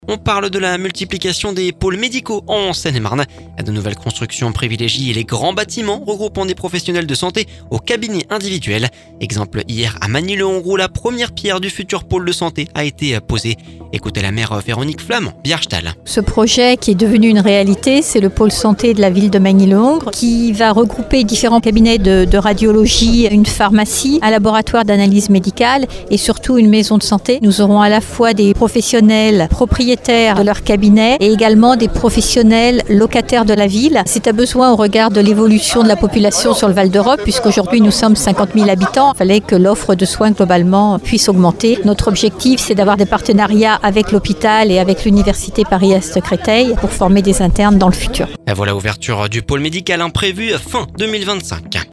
La maire Véronique Flament Bjarstal au micro d'Oxygène.